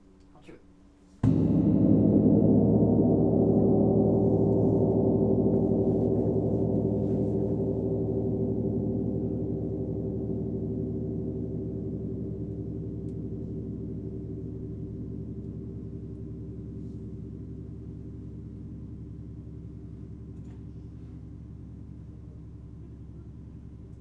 Grand_piano.wav